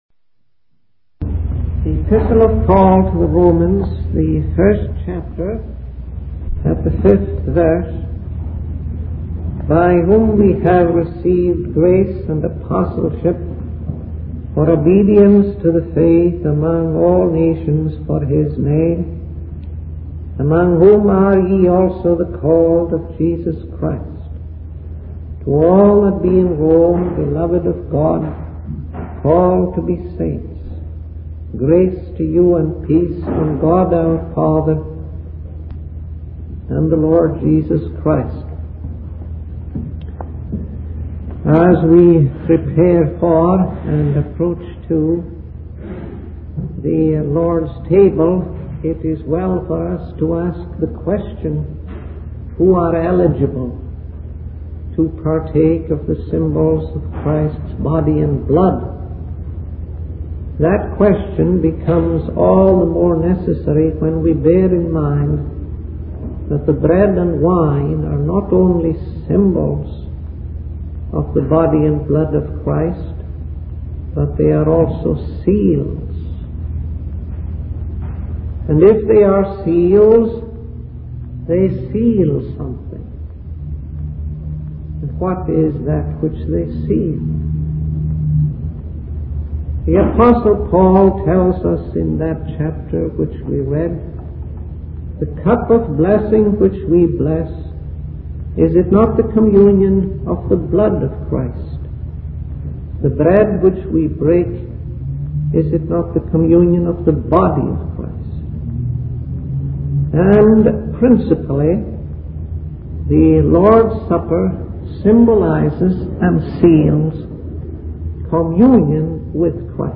In this sermon, the preacher emphasizes the love of God towards believers. He explains that God's love for us is not based on our own goodness or worthiness, but rather on His own grace and sovereignty.